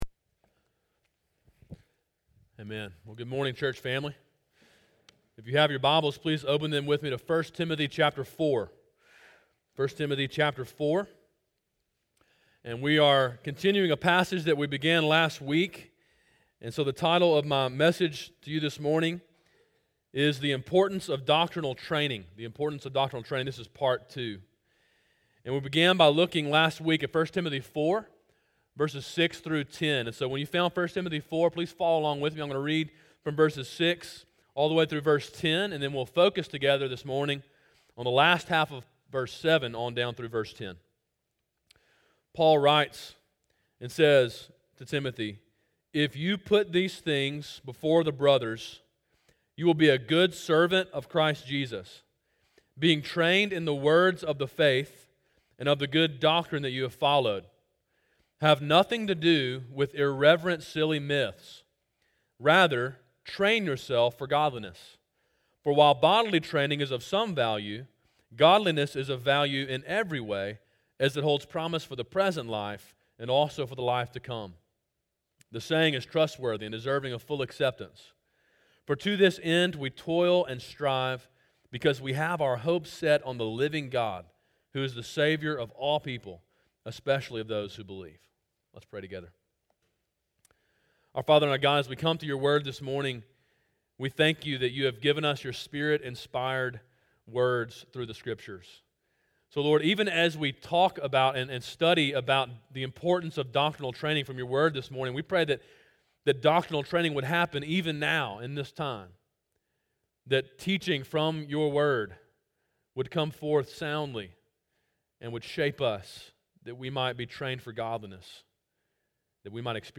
A sermon in a series on the book of 1 Timothy.